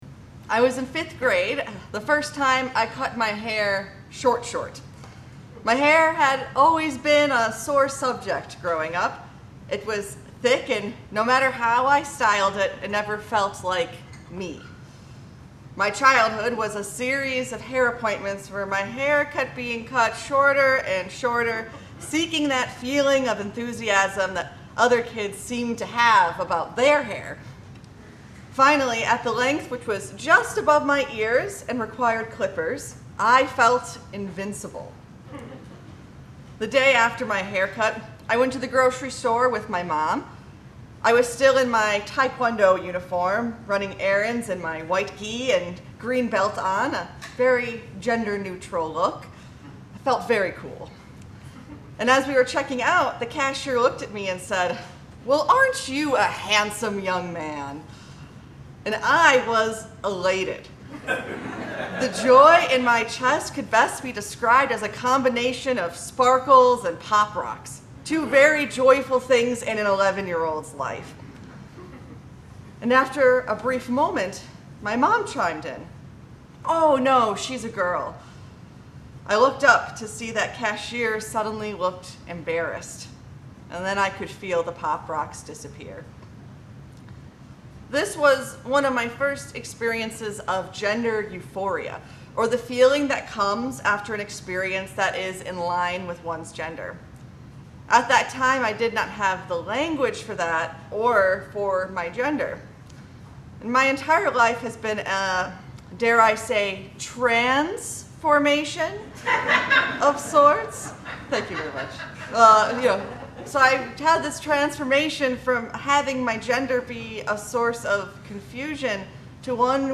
Order of Service